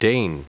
Prononciation du mot deign en anglais (fichier audio)
Prononciation du mot : deign